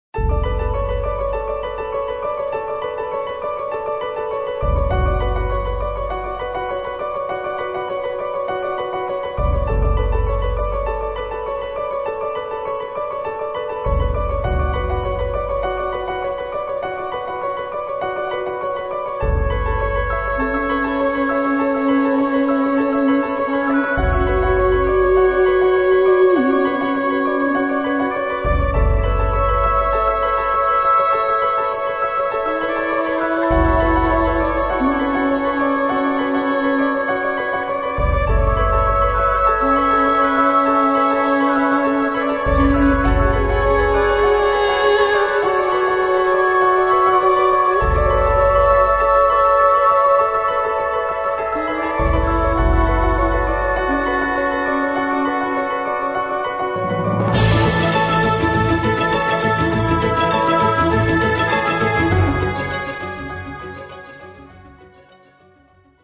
美メロ悲哀サウンドの極致
vocals, piano
cello
oboe
クラシカルで美しいアルバム。静かで落ち着いた雰囲気ですが、時にダイナミックに盛り上がります。